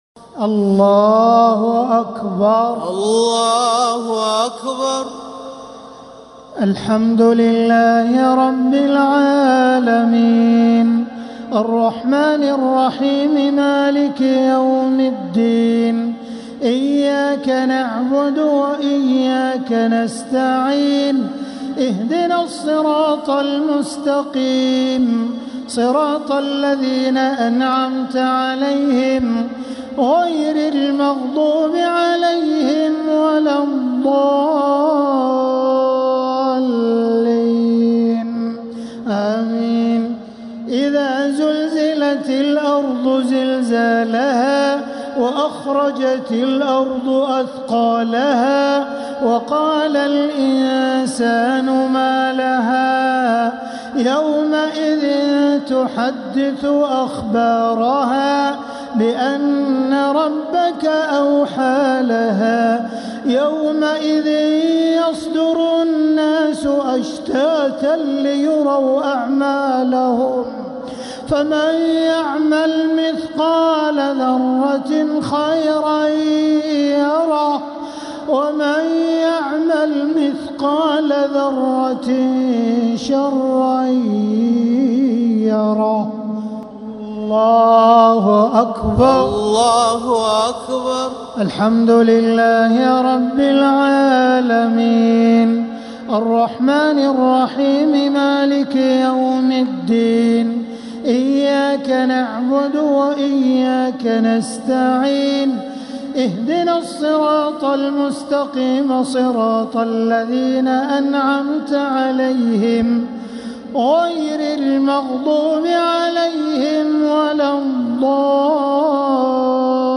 الشفع و الوتر ليلة 10 رمضان 1447هـ | Witr 10th night Ramadan 1447H > تراويح الحرم المكي عام 1447 🕋 > التراويح - تلاوات الحرمين